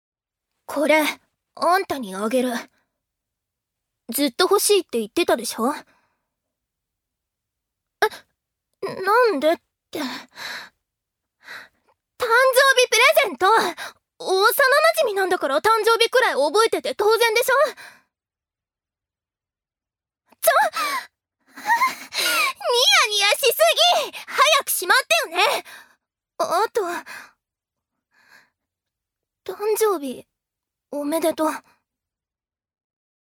女性タレント
音声サンプル
セリフ１